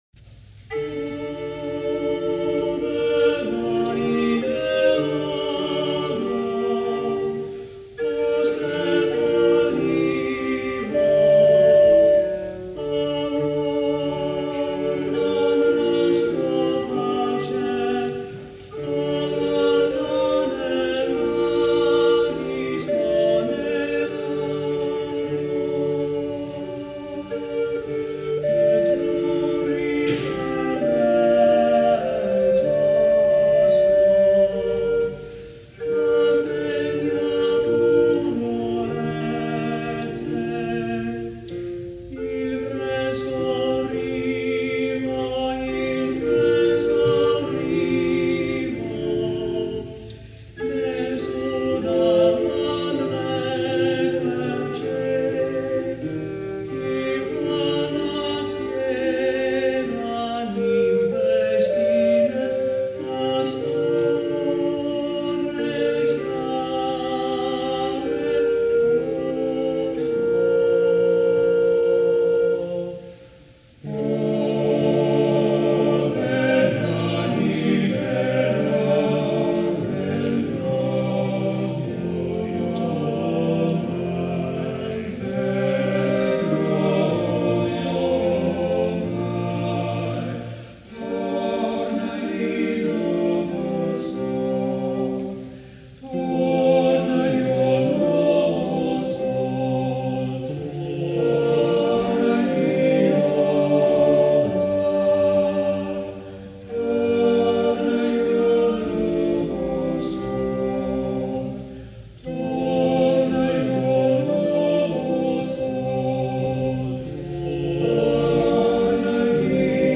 This madrigal a4 supplied the third intermedio of Cosimo’s wedding entertainment.
Here we use a solo voice and three instruments.
tenor